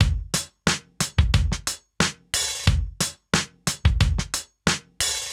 Index of /musicradar/sampled-funk-soul-samples/90bpm/Beats
SSF_DrumsProc2_90-01.wav